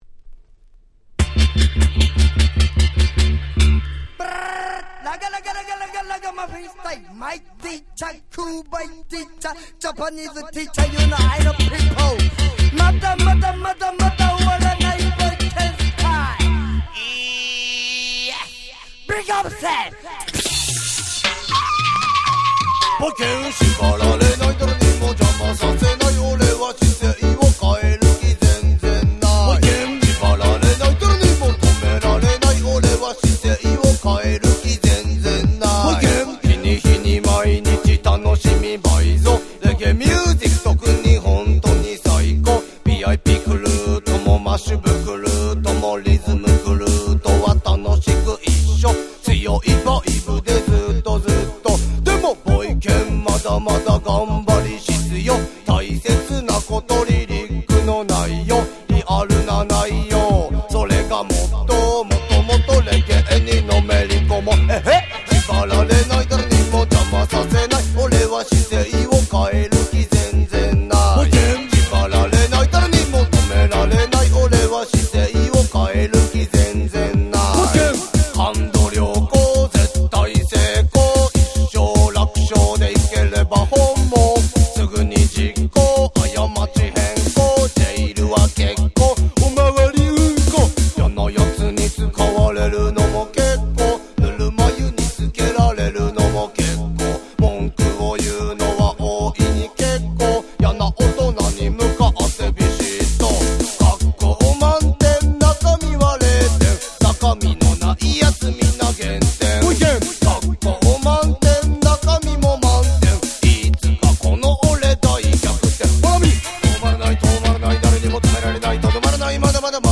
91' Japanese Reggae Classics !!